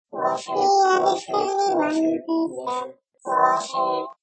Water! Download It's not the best jingle; I lacked inspiration.